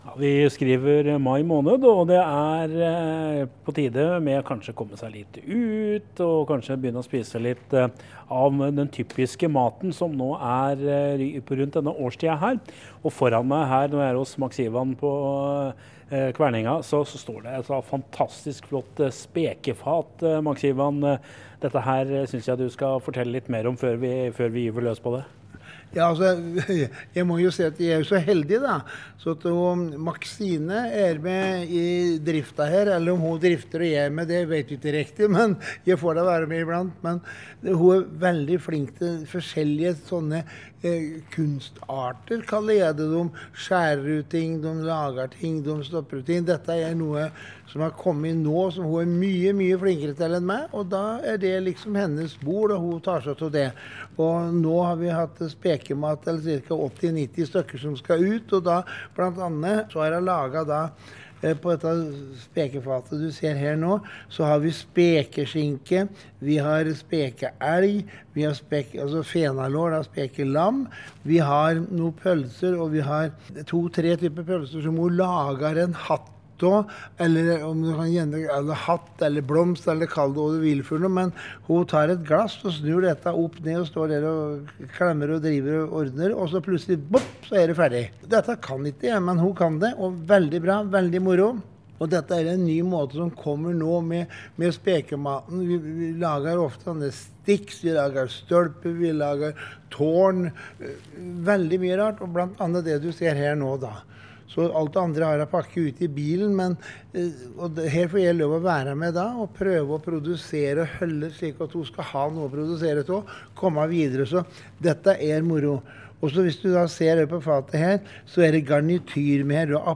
Hør innslaget fra ettermiddagssendinga her: